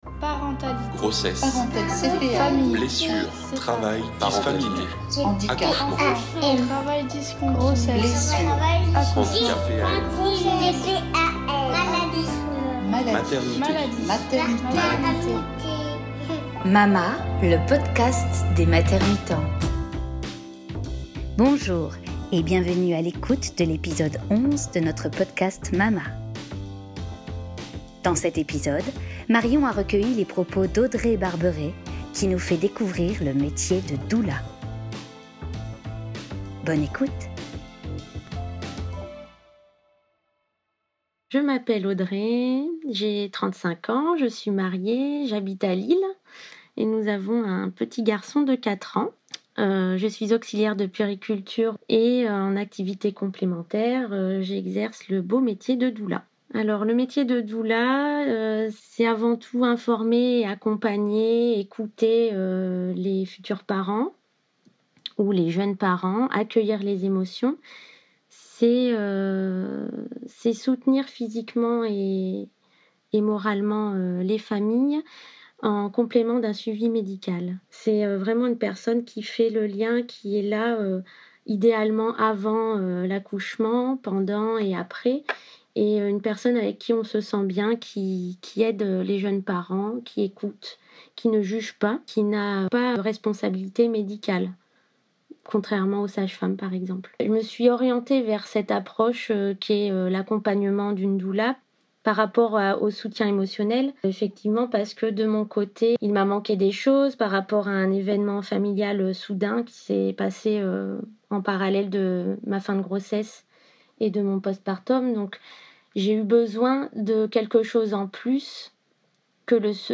Episode #11 - Entretien avec une doula